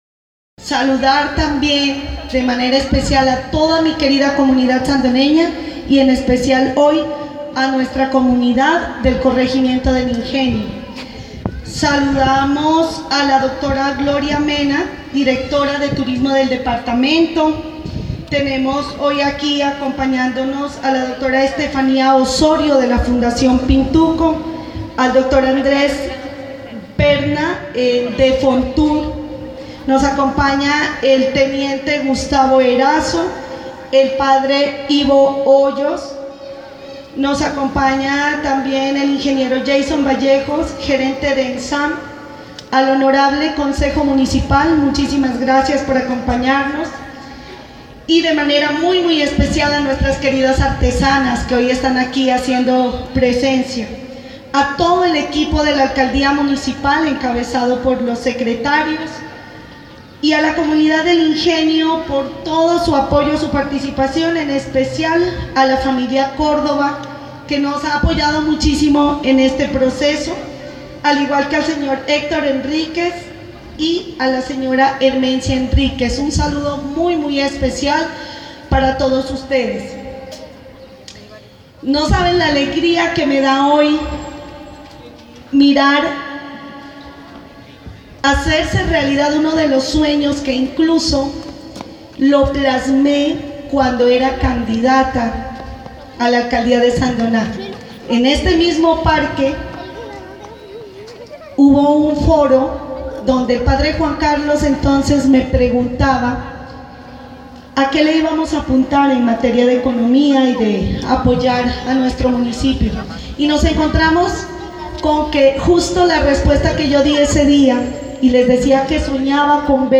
En el parque de la vereda Ingenio Centro este martes en la mañana se realizó el lanzamiento del programa “Sandoná pueblo que enamora, con arte pintura y color”.